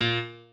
piano7_32.ogg